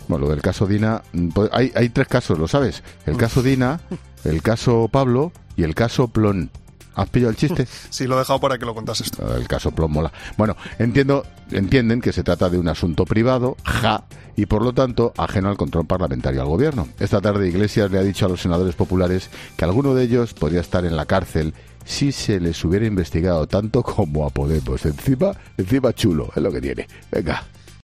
Ángel Expósito analiza la No comparecencia de Pablo Iglesias en el congreso por el caso Dina
El presentador de La Linterna, Ángel Expósito, ha recalcado que existen tres casos "el caso Dina, el caso Pablo y el caso-Plón".